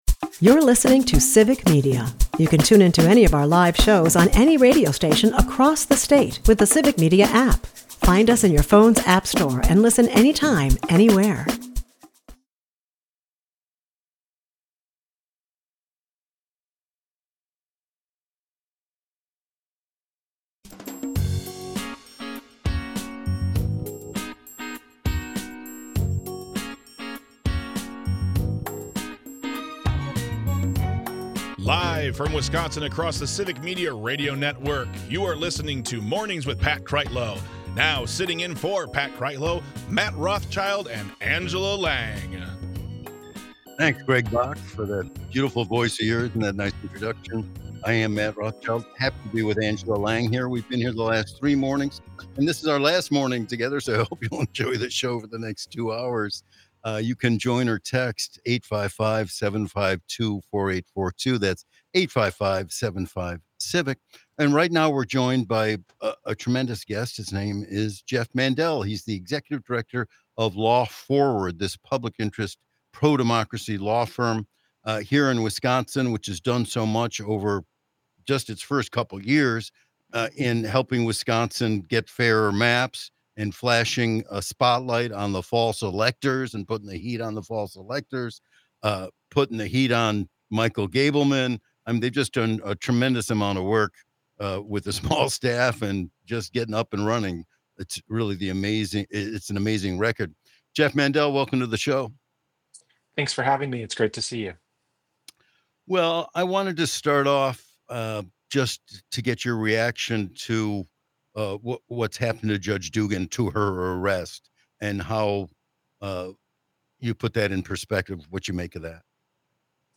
Welcome to the best of episode!!
we have a great collection of discussions and interviews